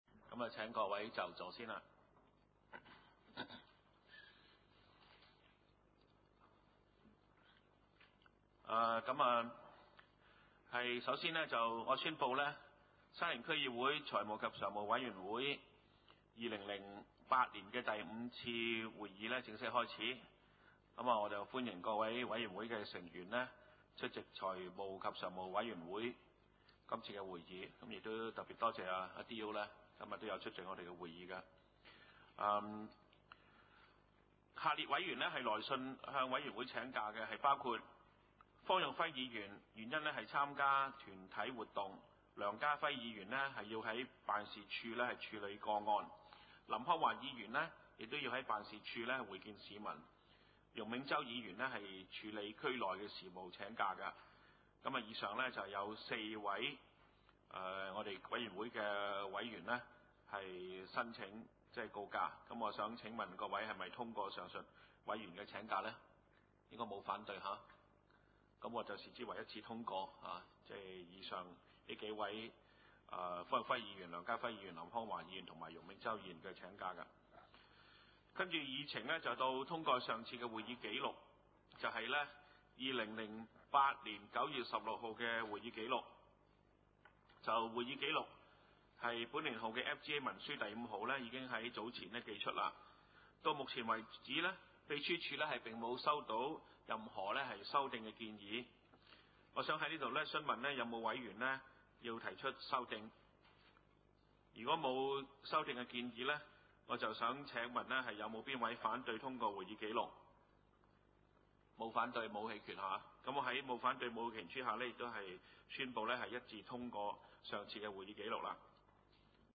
: 沙田區議會會議室